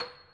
piano-sounds-dev
Steinway_Grand
c7.mp3